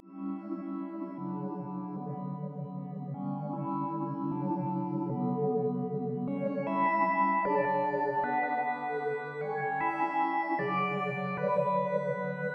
Gold_PAD.wav